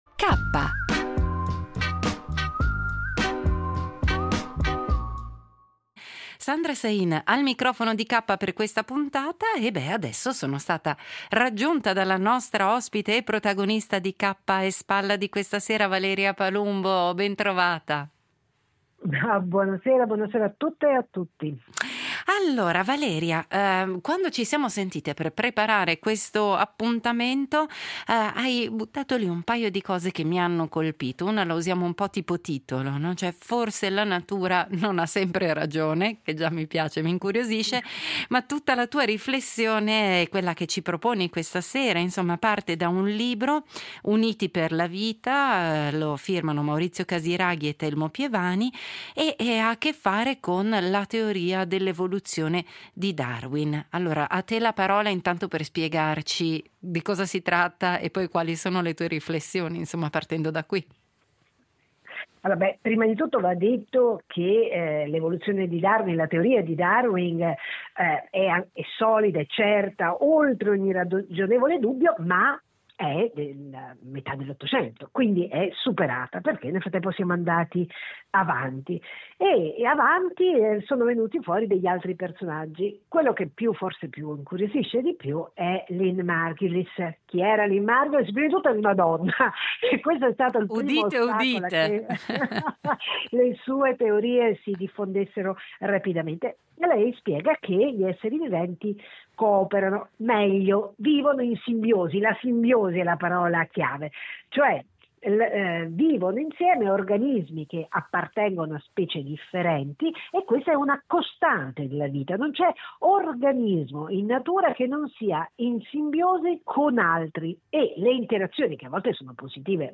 L’editoriale del giorno